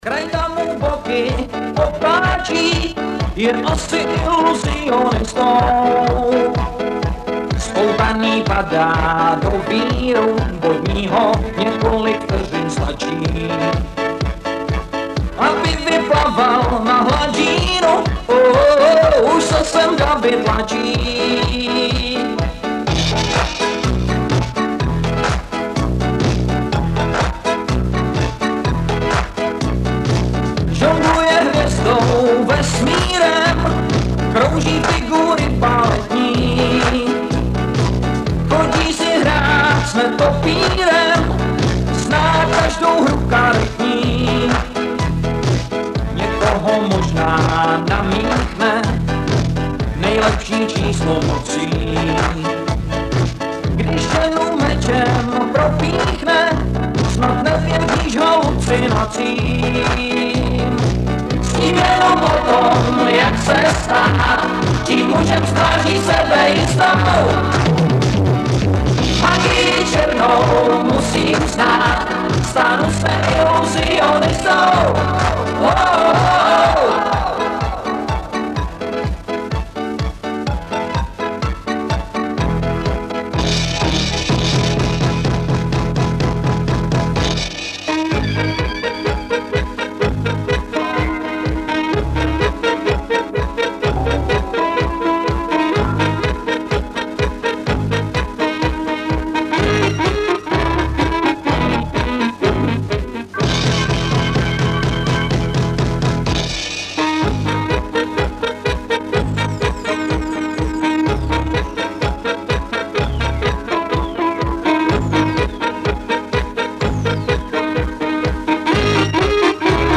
Tak výsledek z mého mobilu je špatný, je to úplně jiná skladba...cizí. Je to buď neoriginální nahrávka nebo nekvalitní.